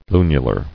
[lu·nu·lar]